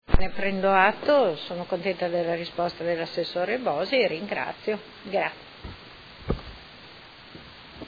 Seduta del 12/12/2019. Conclude interrogazione della Consigliera Santoro (Lega Modena) avente per oggetto: Museo Enzo Ferrari